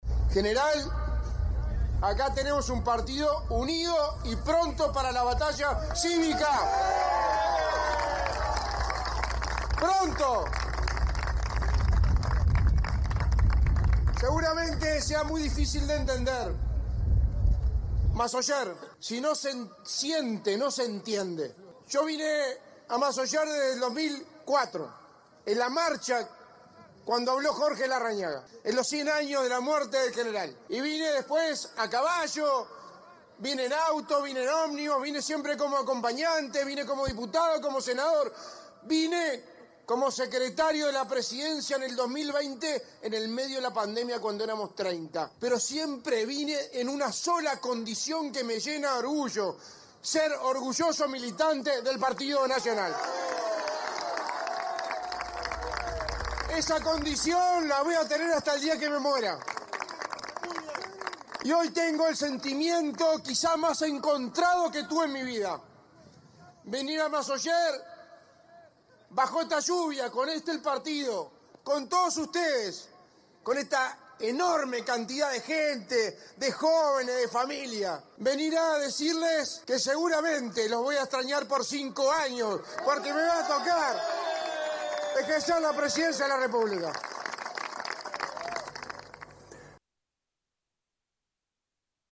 La fórmula presidencial del PN participó de la Marcha a Masoller, en homenaje a Aparicio Saravia - 970 Universal
Escuche a Álvaro Delgado aquí: